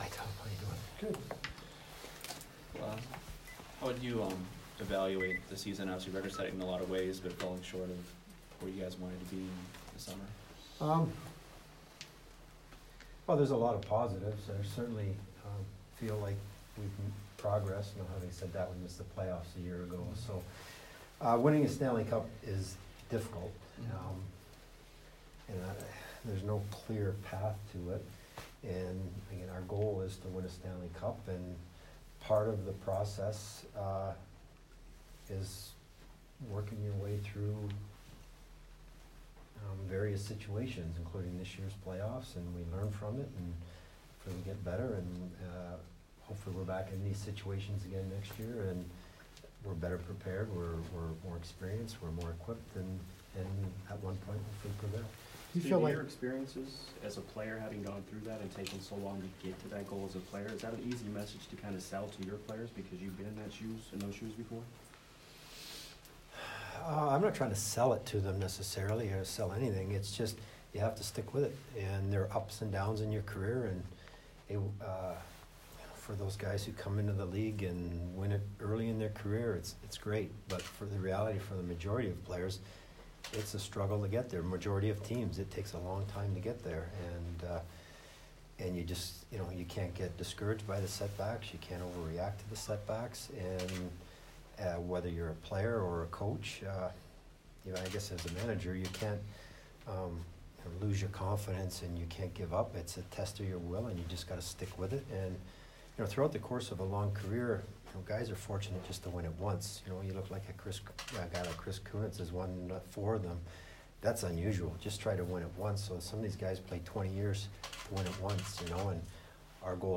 Steve Yzerman Exit Interview 5/24